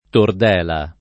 vai all'elenco alfabetico delle voci ingrandisci il carattere 100% rimpicciolisci il carattere stampa invia tramite posta elettronica codividi su Facebook tordela [ tord % la o tord $ la ] o tordella [ tord $ lla ] s. f. (zool.)